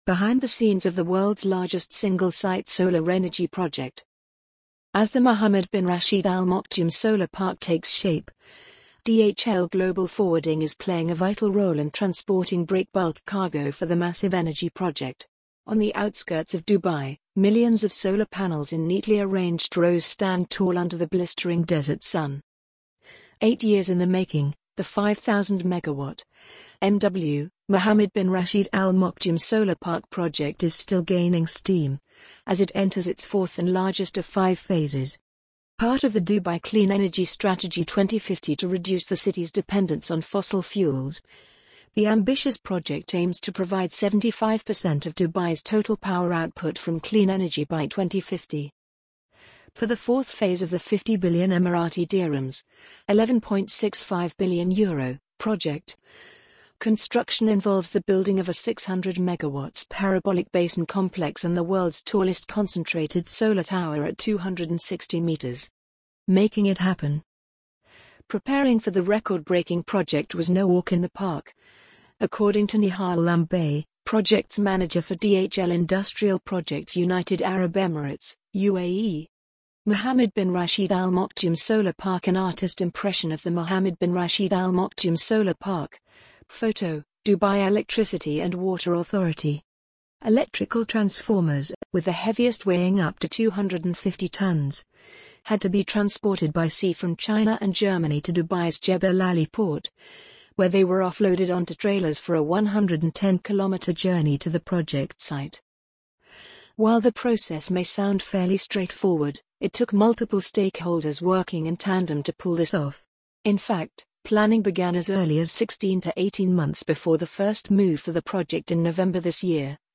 amazon_polly_9709.mp3